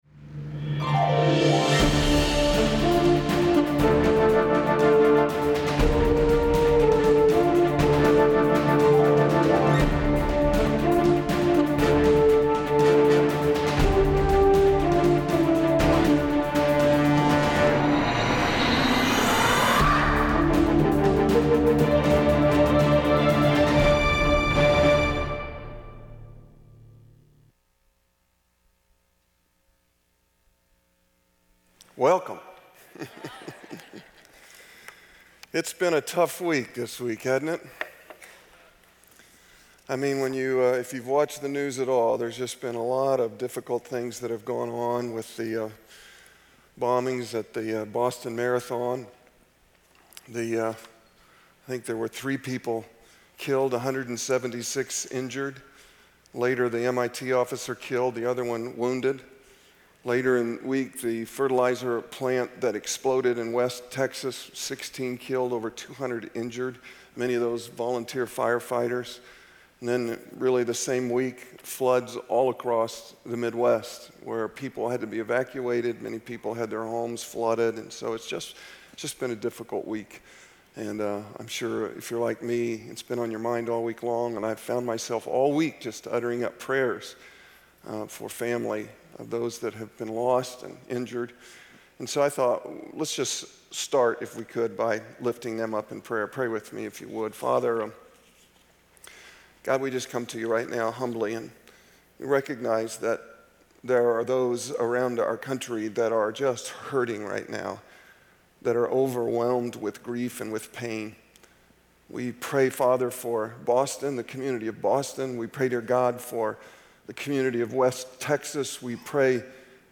What are the "giants" in your life? Lead Pastor